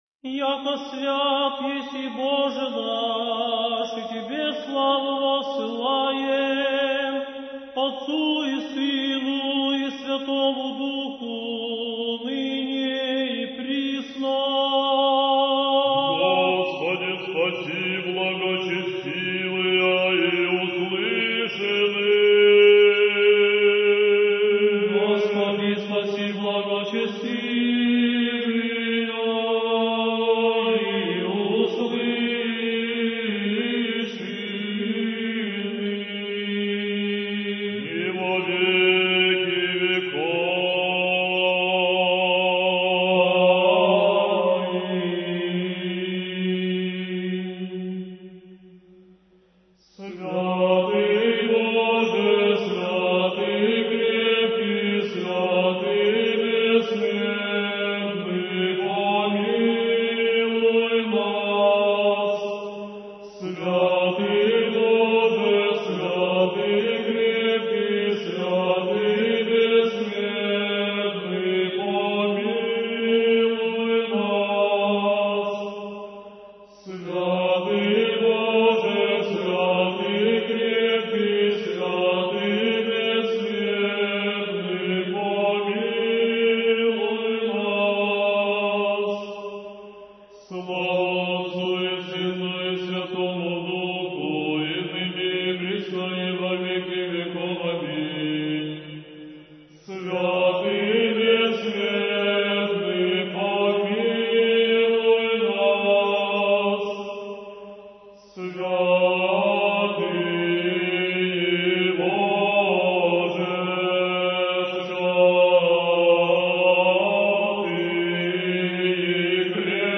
Архив mp3 / Духовная музыка / Русская / Ансамбль "Сретение" /